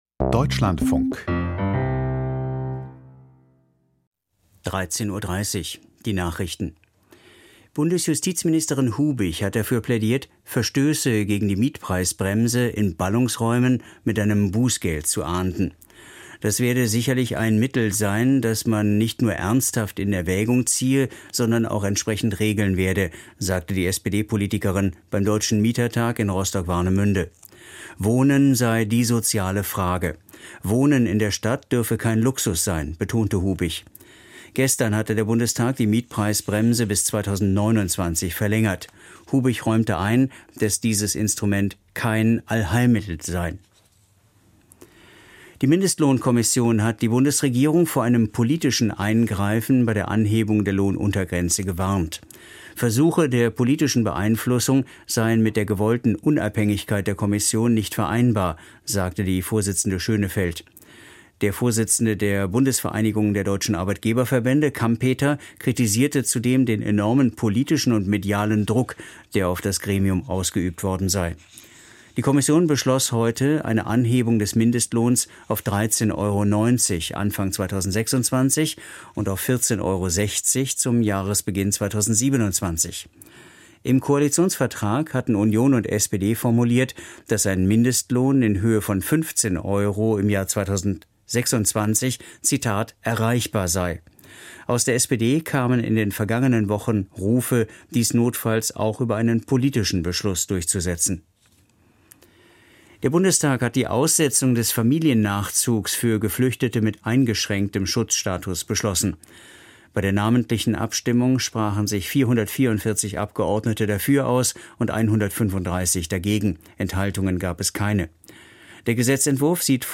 Die Nachrichten vom 27.06.2025, 13:30 Uhr